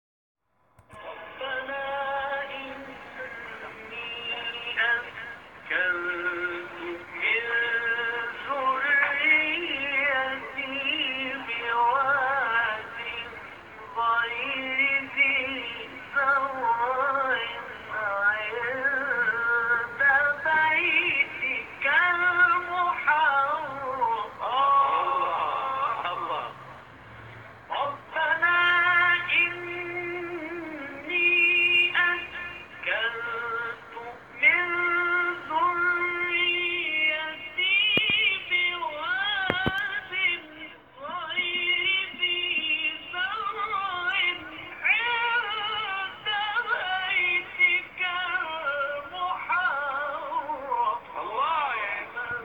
مقام-نهاوند.m4a